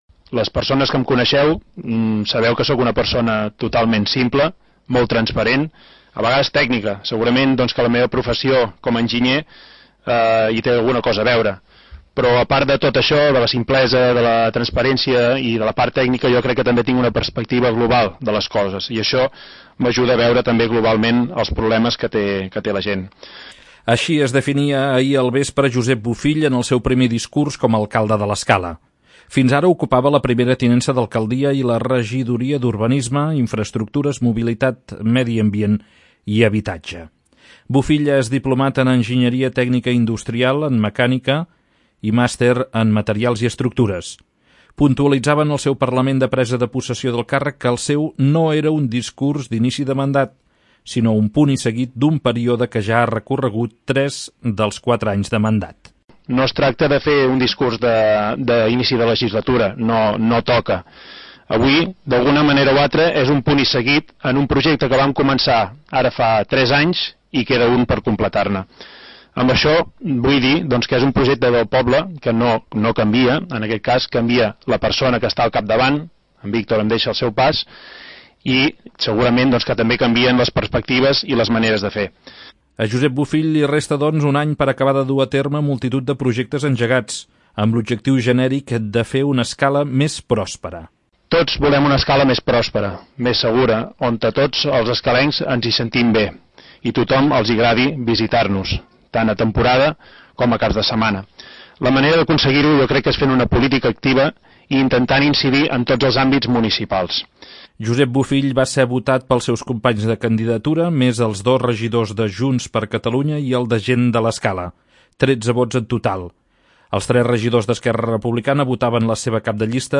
2. L'Informatiu
Així es definia ahir al vespre Josep Bofill, en el seu primer discurs com a alcalde de l'Escala.
Va ser un ple seguit per un nombrós públic que omplia a vessar la nova sala de plens.